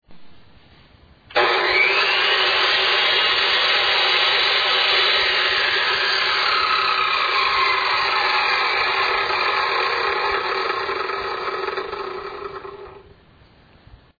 使えるんだけど、音を聞く限り、どうもベアリングが怪しそう。
ベアリング交換前
通電OFF後、回転停止までの堕走時間は、交換前は約7秒、交換後は約12秒と長くなっている。
shindaiwa-l100p-b4.mp3